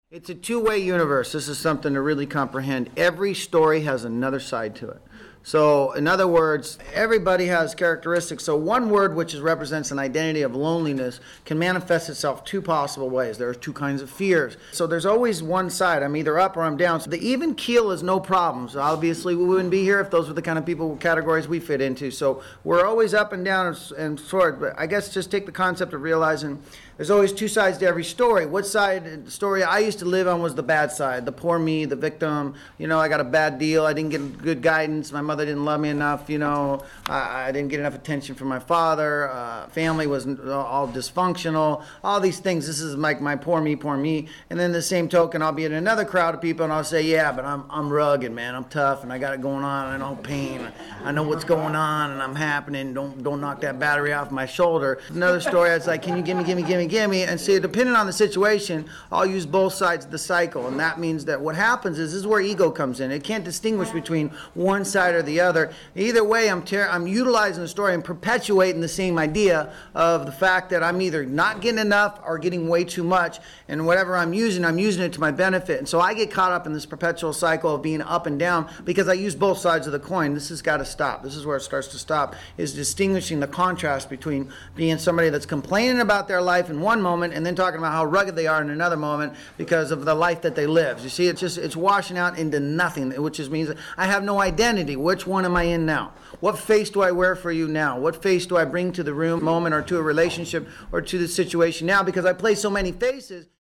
This engaging discussion addresses the challenges of identifying and acknowledging personal resentments, particularly through the process of self-inventory.